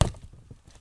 描述：塑料，豆腐渣，打击乐，打，踢，自制，山寨，地窖，木棚
Tag: 家庭 山寨 储罐 地窖 塑料 打击乐 制作